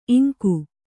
♪ iŋku